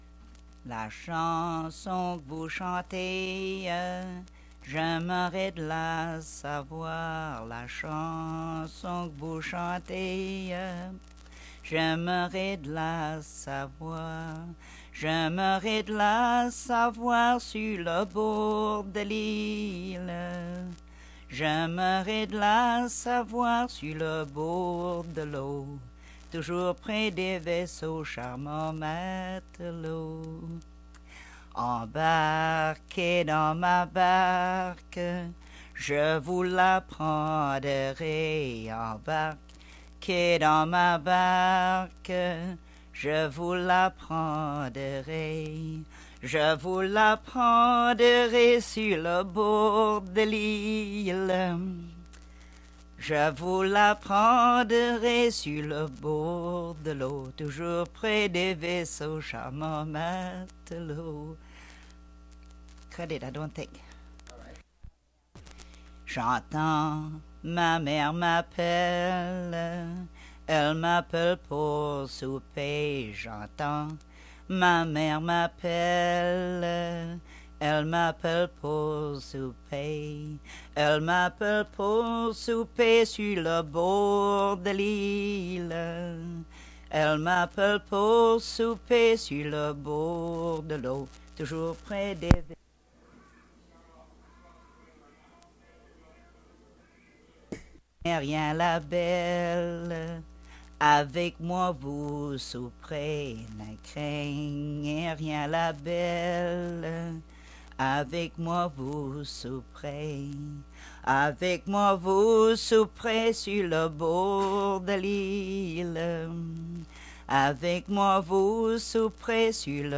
Chanson Item Type Metadata
Emplacement L'Anse-aux-Canards